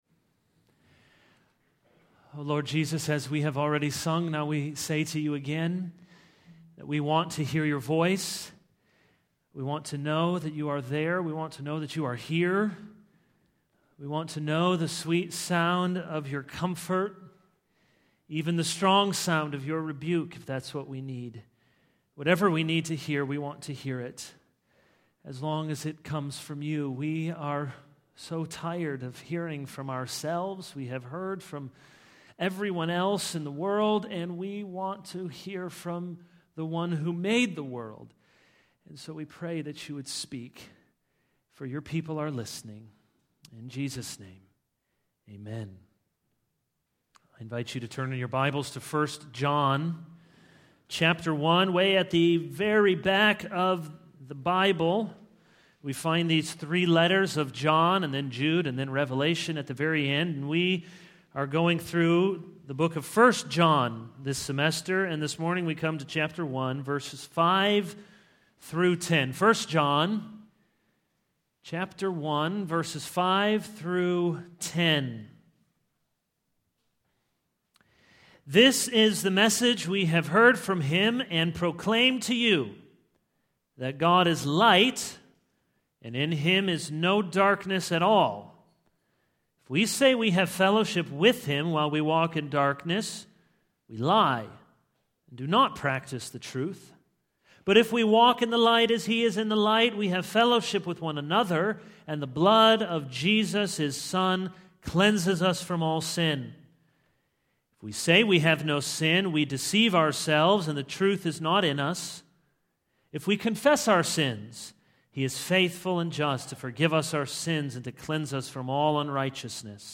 This is a sermon on 1 John 1:5-10.